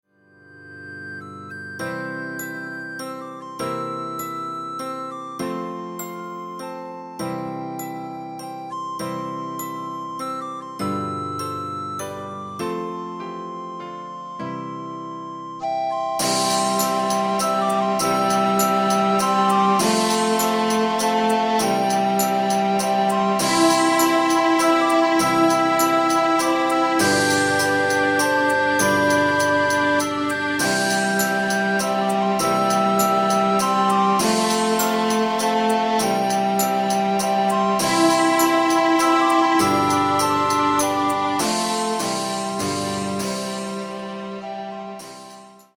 Primary School Classroom Teaching Ensemble